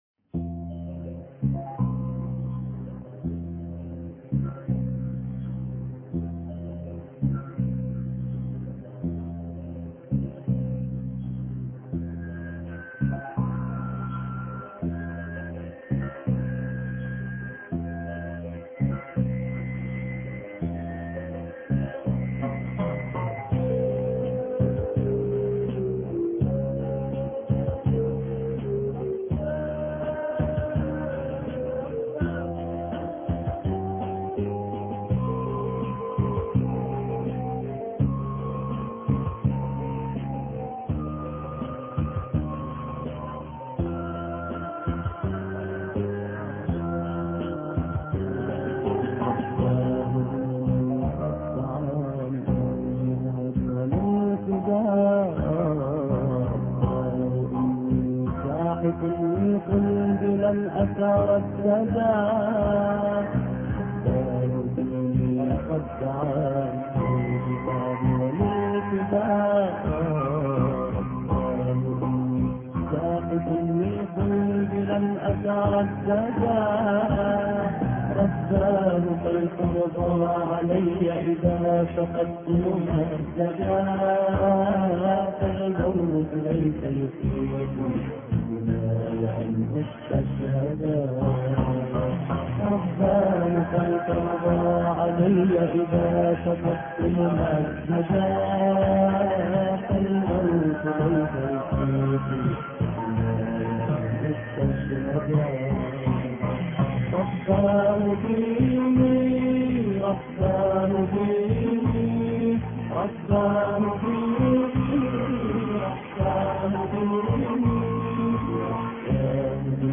رباه ديني الأحد 16 مارس 2008 - 00:00 بتوقيت طهران تنزيل الحماسية شاركوا هذا الخبر مع أصدقائكم ذات صلة الاقصى شد الرحلة أيها السائل عني من أنا..